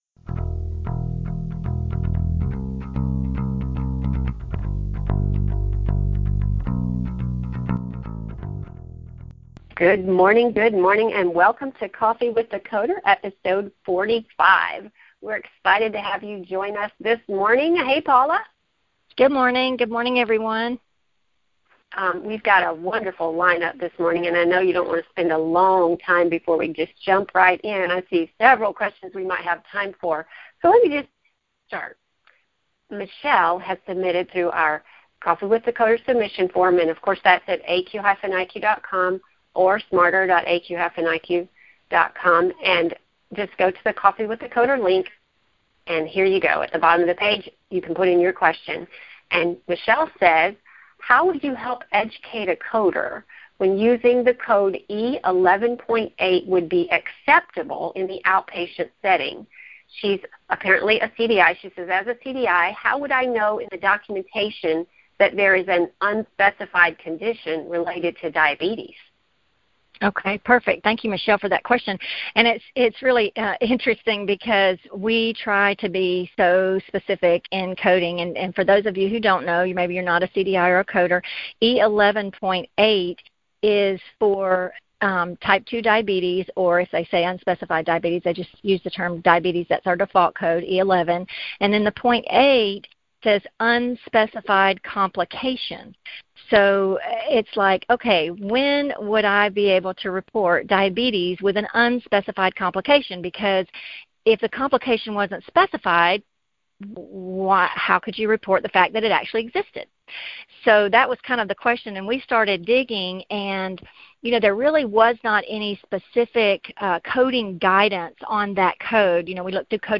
Finally, one caller asks for a code for open deep excision of inguinal lymph nodes.